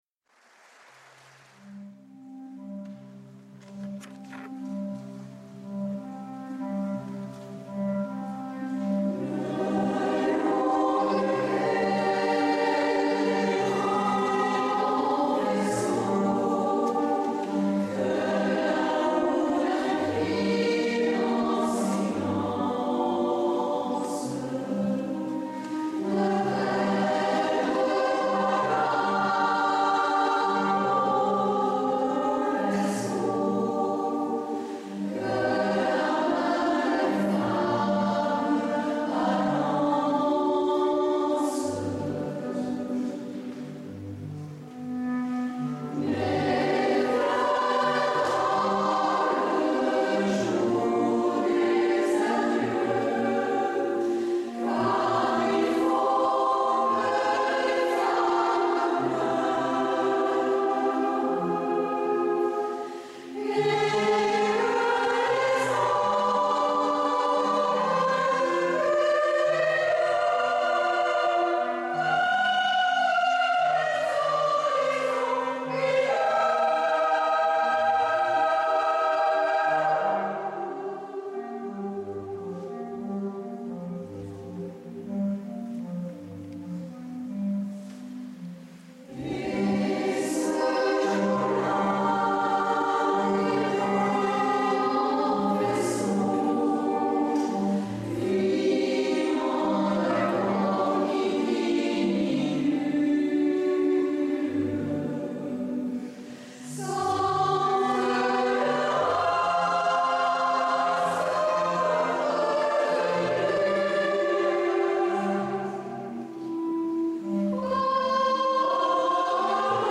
C’est un instrument de musique au timbre chaud et lumineux avec une sonorité profonde, sensuelle, chaude et pénétrante.
Quelques enregistrements dans lesquels nous pouvons entendre la clarinette basse.
accompagné le chœur féminin de Résonances en concert à Parisot.